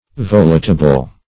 volitable - definition of volitable - synonyms, pronunciation, spelling from Free Dictionary
Volitable \Vol"i*ta*ble\, a.